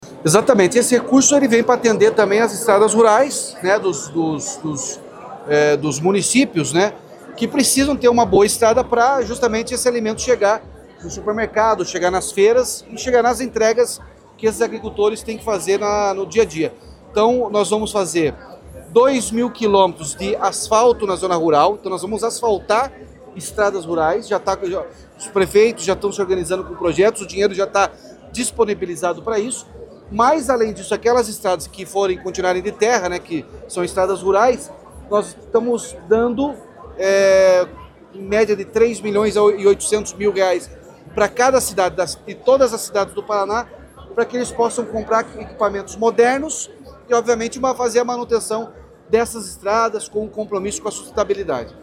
Sonora do governador Ratinho Junior sobre a liberação de R$ 100 milhões para máquinas para estradas rurais na RMC